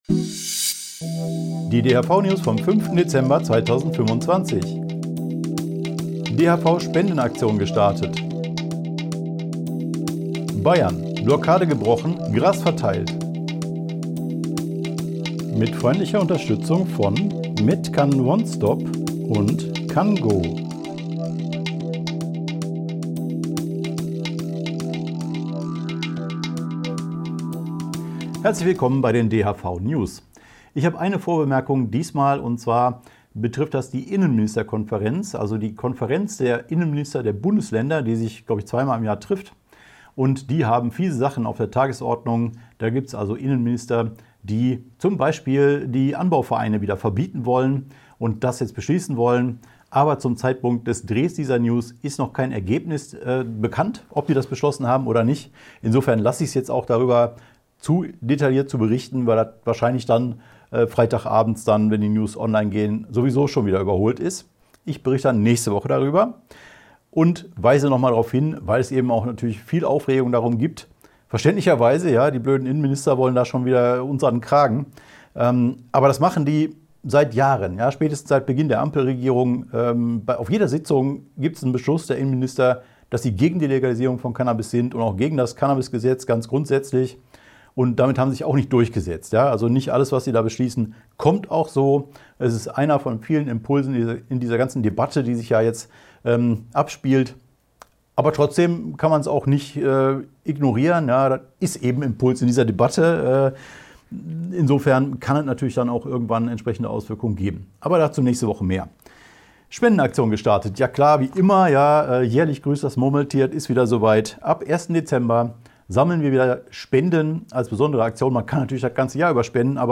DHV-News # 489 Die Hanfverband-Videonews vom 05.12.2025 Die Tonspur der Sendung steht als Audio-Podcast am Ende dieser Nachricht zum downloaden oder direkt hören zur Verfügung.